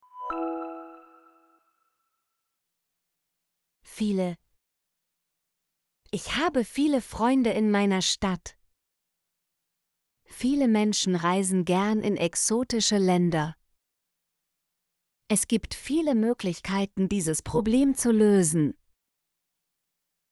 viele - Example Sentences & Pronunciation, German Frequency List